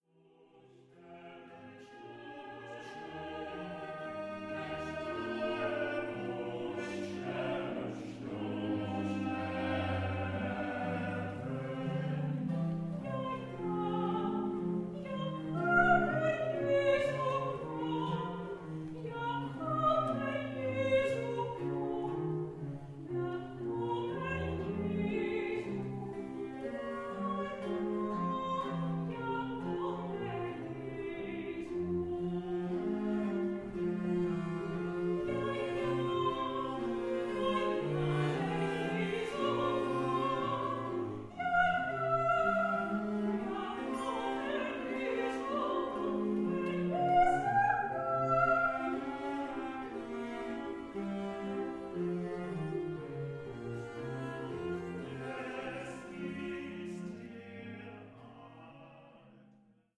'Fac ut portem', aria uit G.B. Pergolesi, Stabat Mater
Live-opname Lutherse Kerk Hoorn (2014)